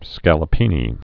(skălə-pēnē, skälə-)